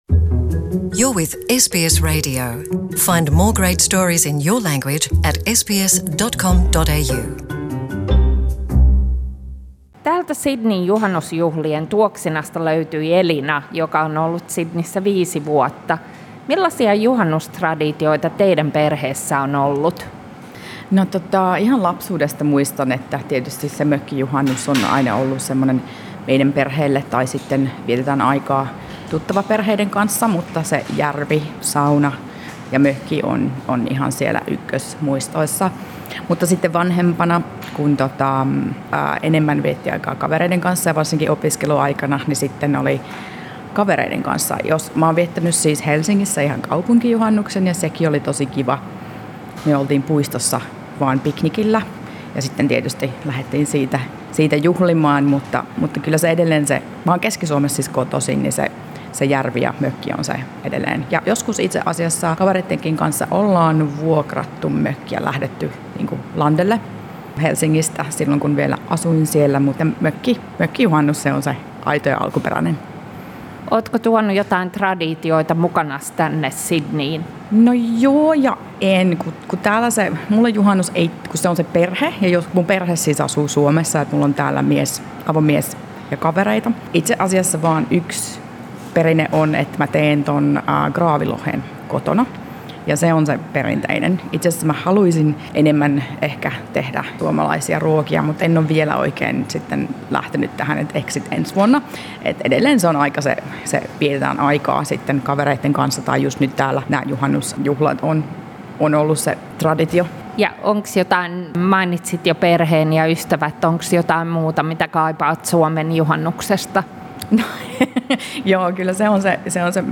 Sydneyn juhannusjuhlat 2019